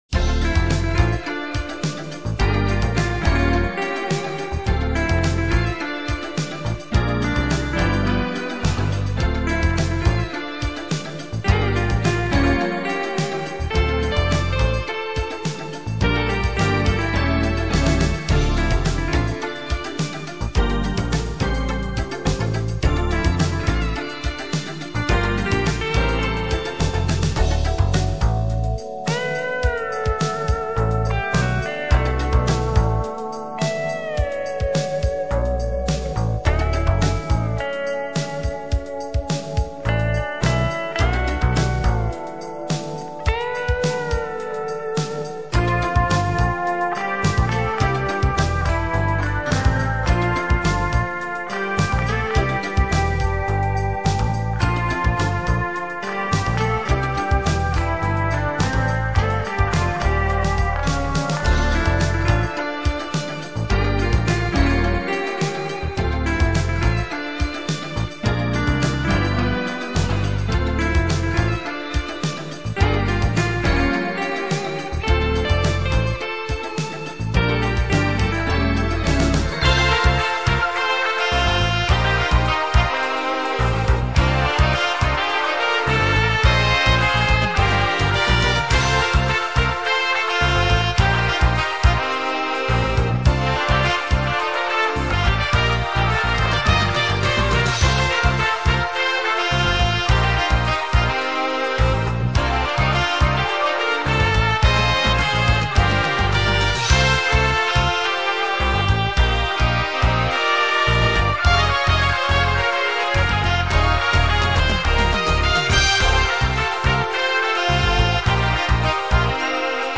Guitar
Kbds